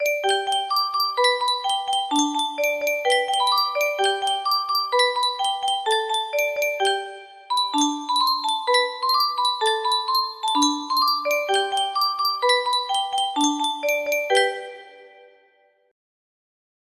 Clone of Yunsheng Spieluhr - Es tanzt ein Bi-Ba-Butzemann 2293 music box melody
Grand Illusions 30 music boxes More